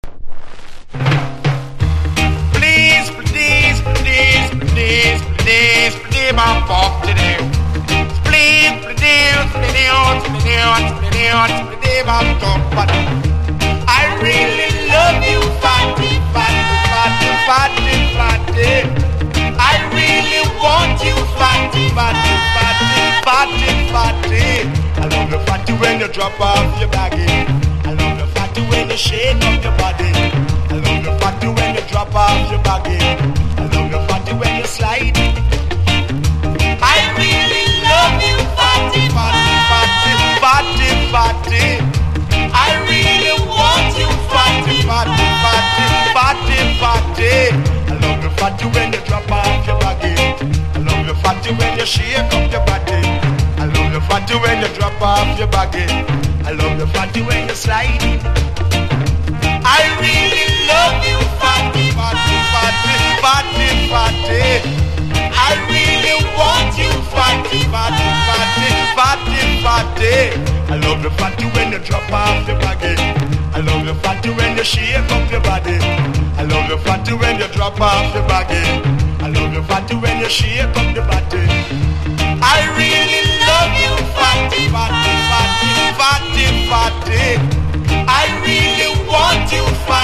• REGGAE-SKA